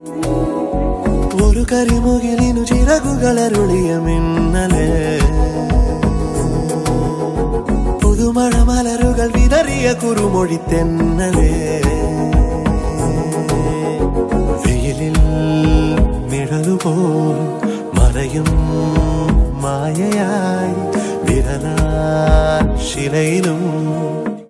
love song ringtone
romantic ringtone download
melody ringtone